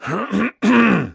throat1.ogg